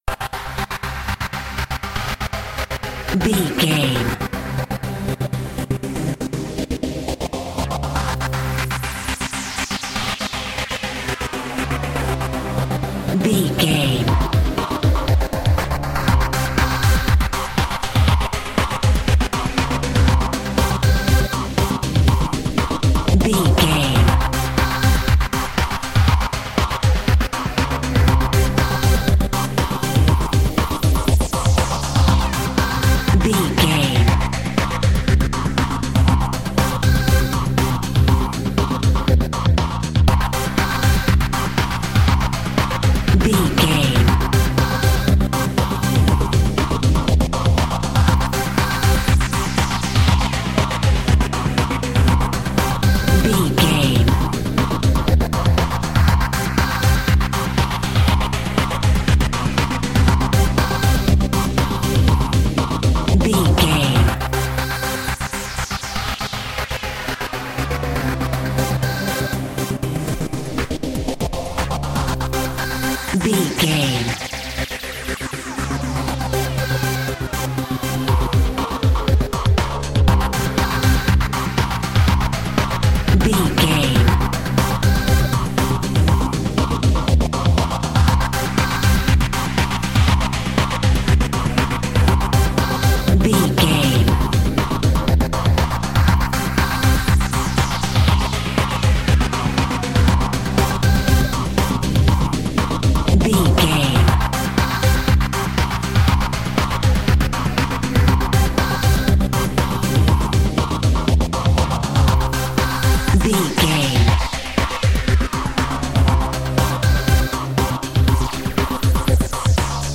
Epic / Action
Aeolian/Minor
drum machine
synthesiser
electric piano
Eurodance